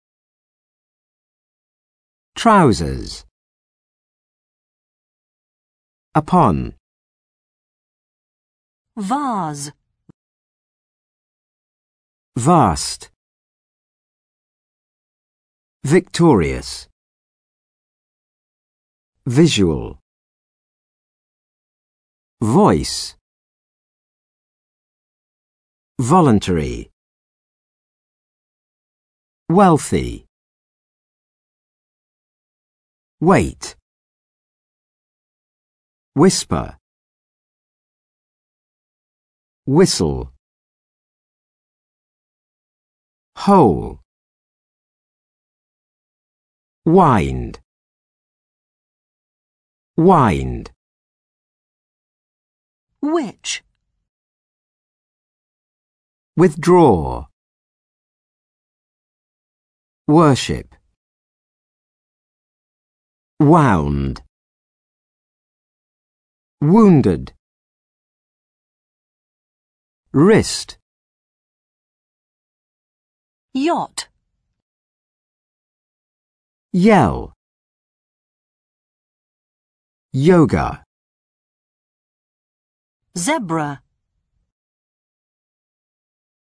Vocabulary practice 12/12
For your vocabulary and pronunciation practice, this glossary presents twenty-five words in alphabetical order with different phonetic sounds.